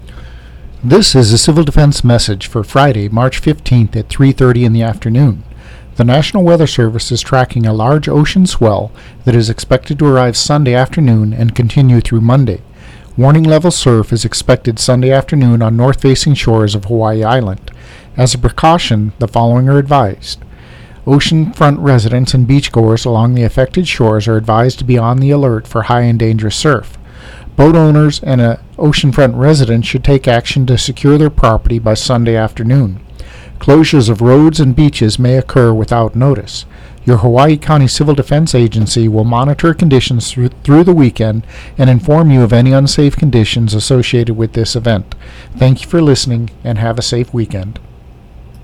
Audio from Hawaiʻi County Civil Defense, released Mar. 15, 2019.